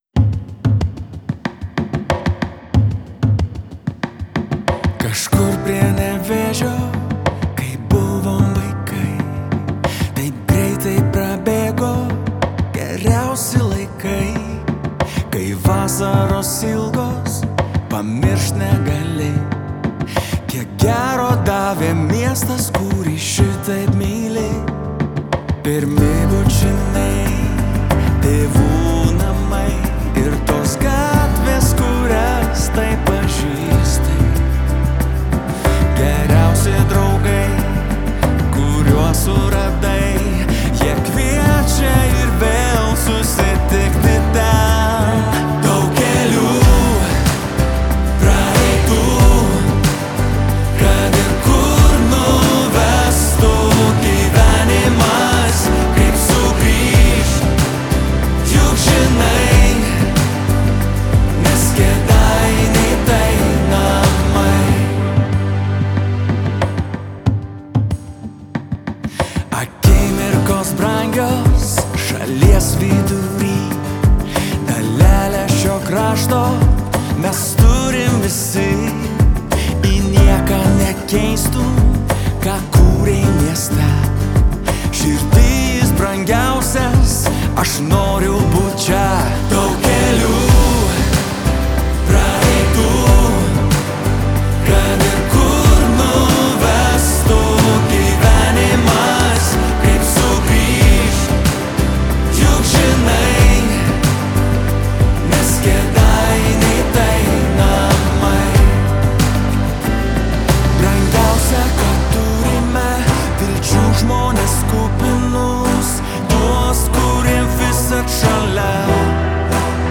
Gitara
Choras